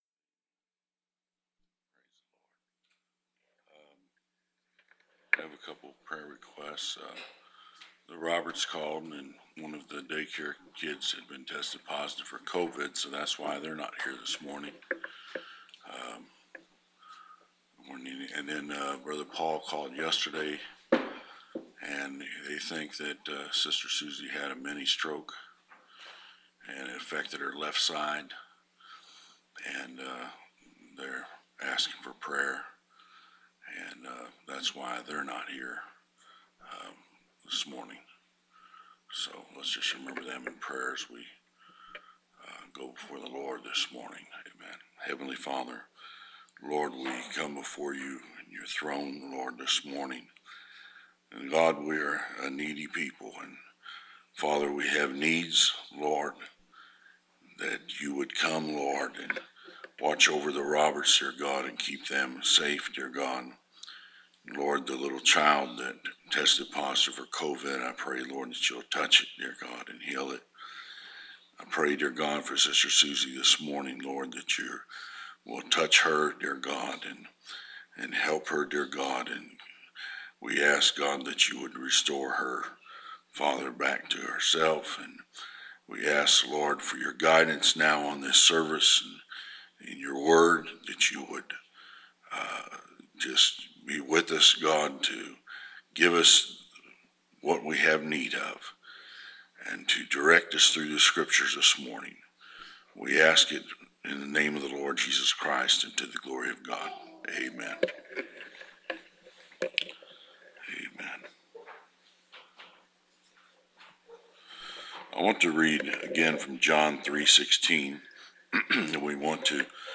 Preached September 26, 2021